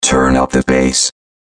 S – TURN UP THE BASS – VOCODE
S-TURN-UP-THE-BASS-VOCODE.mp3